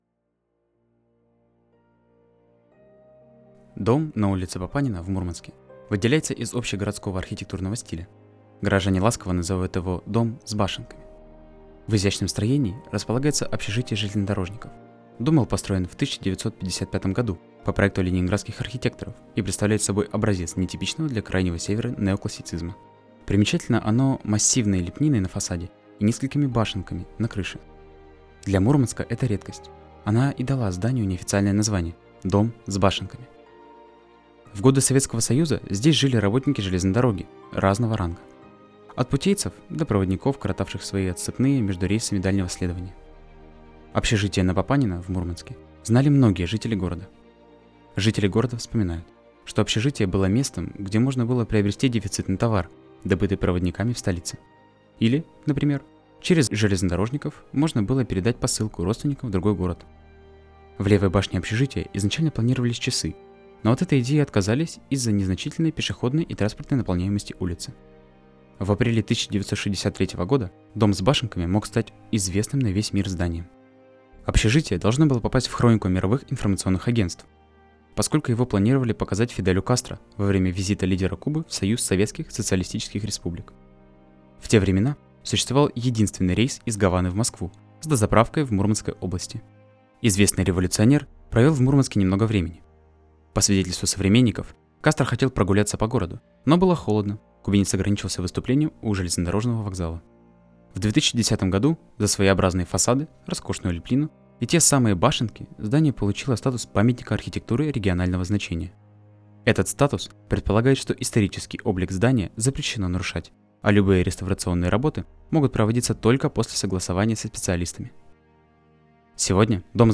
Представляем очередную аудиоэкскурсию в рамках проекта «51 история города М»